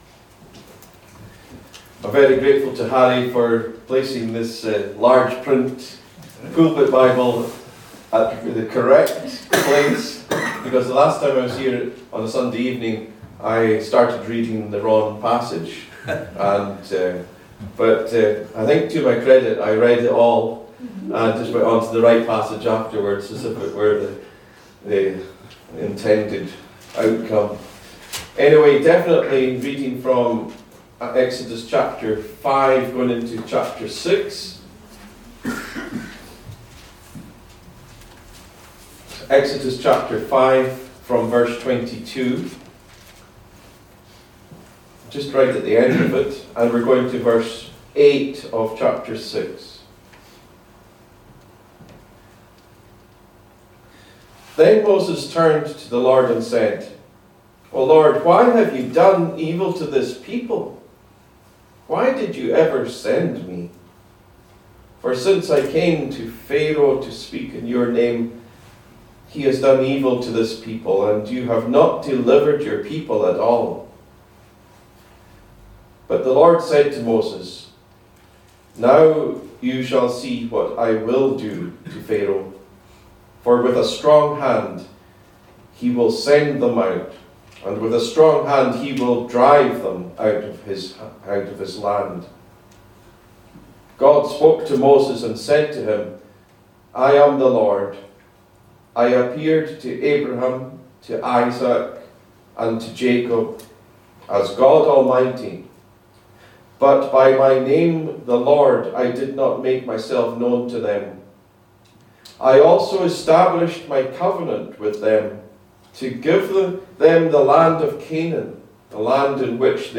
A link to the video recording of the 6:00pm service, and an audio recording of the sermon.
Series: Individual sermons